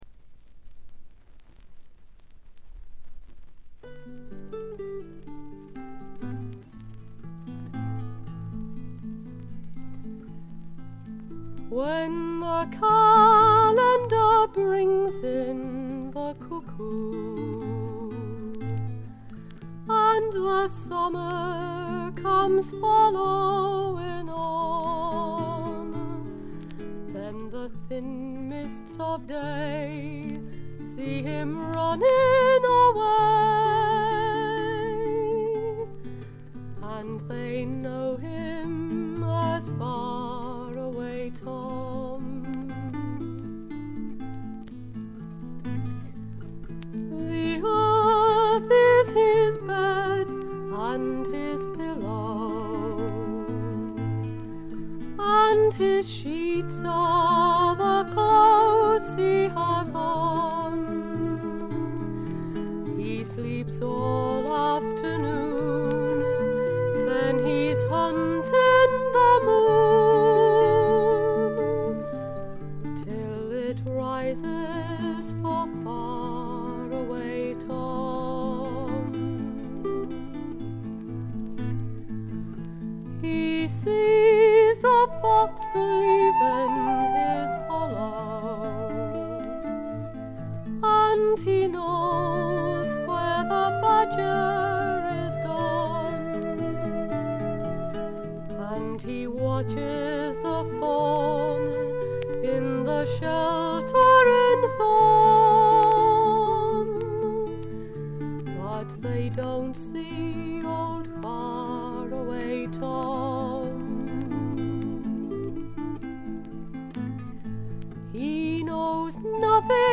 軽微なバックグラウンドノイズ、チリプチ。
試聴曲は現品からの取り込み音源です。
Flute